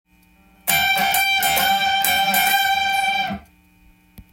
Gミクソリディアンスケールを例にフレーズを作ってみました。
４のフレーズはオクターブチョーキングで
同じ音階をリズミカルにならしたものです。